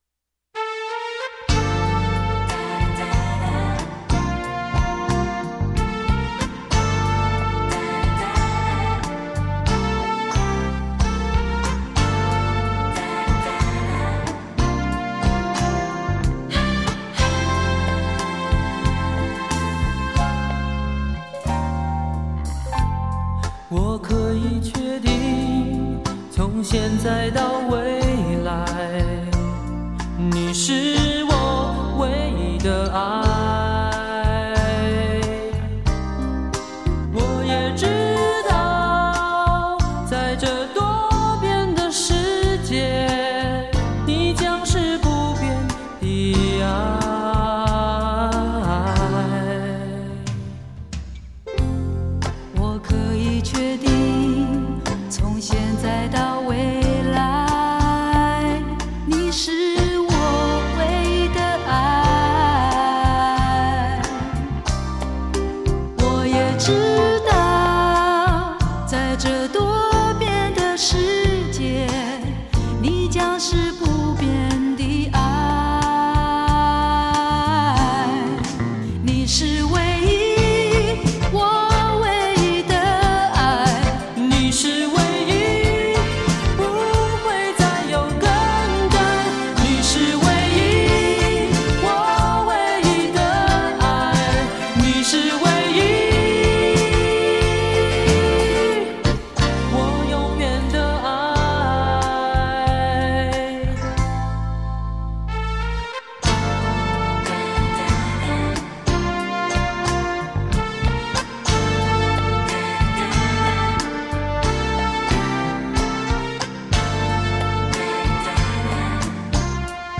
在专辑中与九个男歌手分别对唱情歌